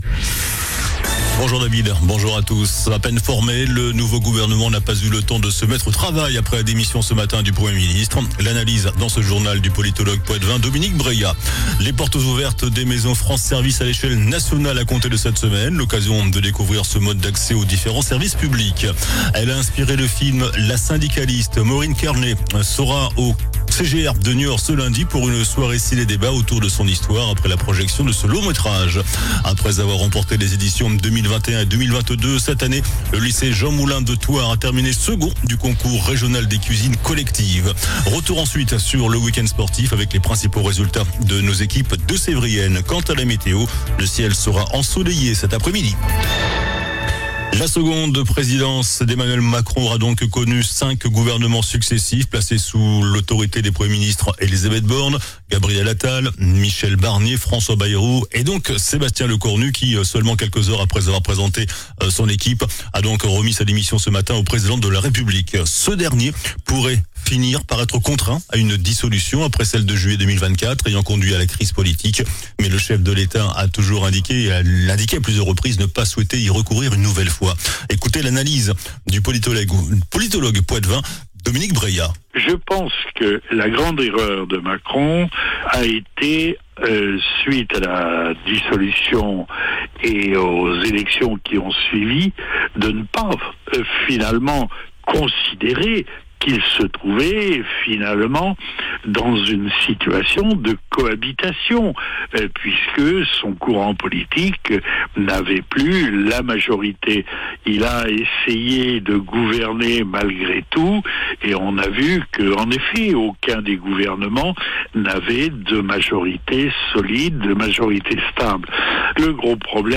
JOURNAL DU LUNDI 06 OCTOBRE ( MIDI )